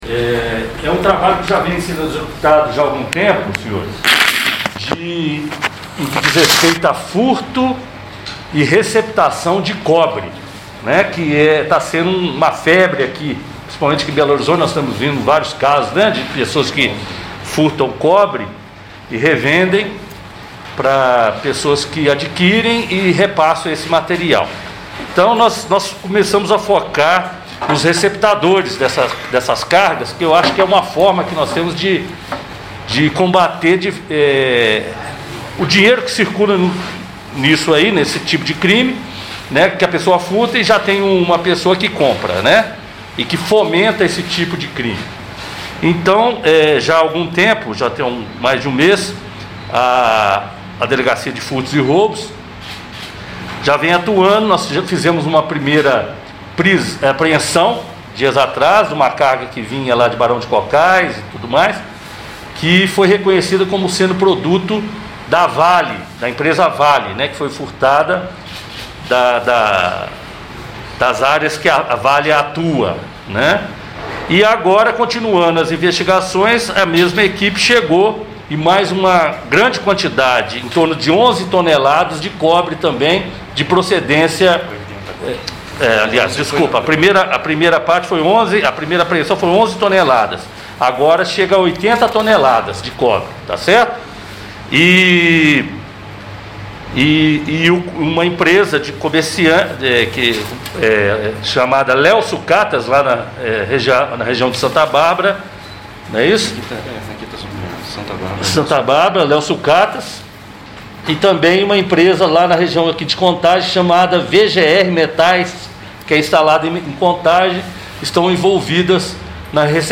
Coletiva-1.mp3